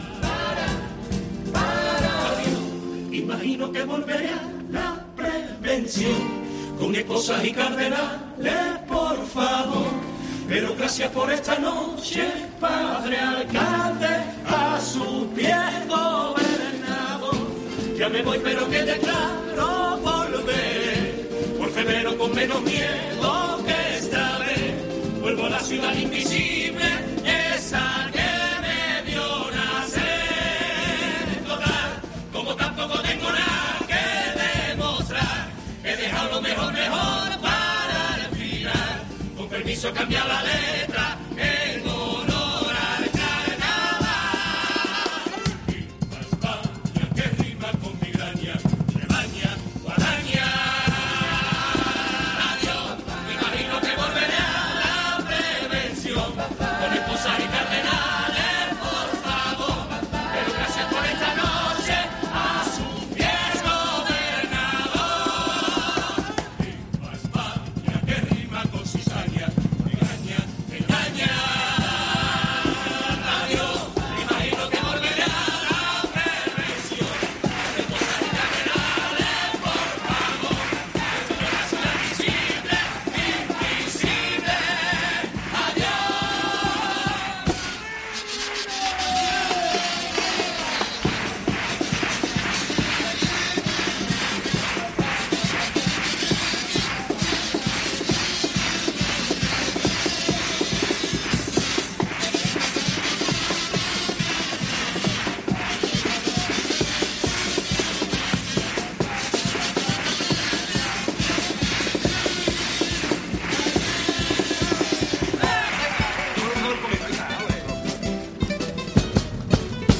Escucho un repaso de las mejores cuartetas de popurrí de este pasado Concurso de Agrupaciones del Gran Teatro Falla